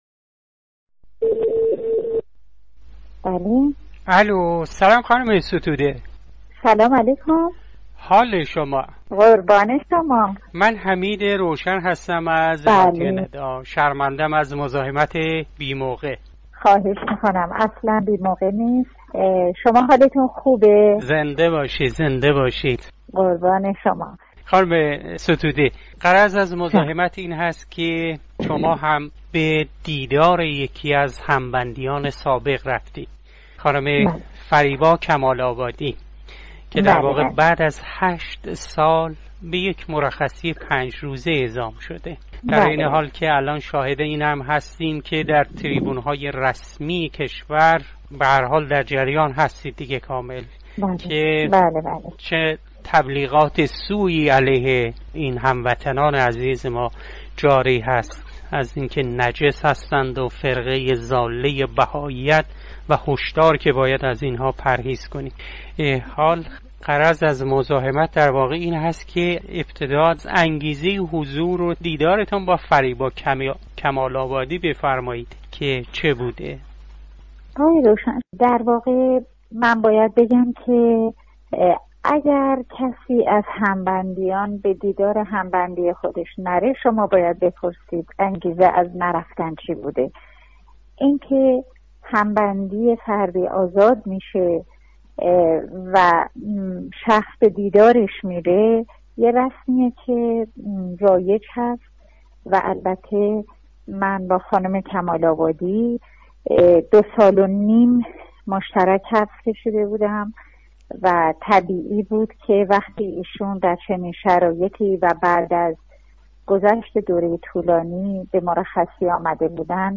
نسرين ستوده، وكيل نام آشنا، در گفتگو با راديو ندا با اشاره به تضييق حقوق شهروندي هموطنان بهائي در جمهوري اسلامي ايران، با تحسين استقامت فريبا كمال آبادي در زندان و با ياد از ديگر هم بندي خود، مهوش ثابت، بعنوان انسان فرهيخته، گفت : '' اميدوارم هر چه زودتر شاهد آزادي '' ياران ايران '' باشيم ! ''